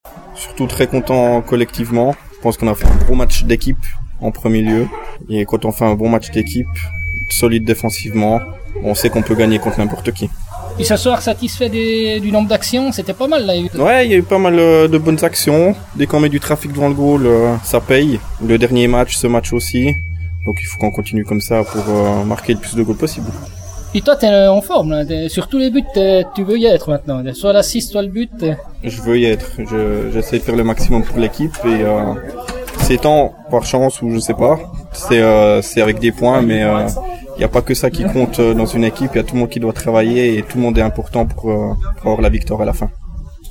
Nous vous proposons sa réaction d’après-match en « exclu web » avant de découvrir un article plus détaillé dans le Courrier du jour :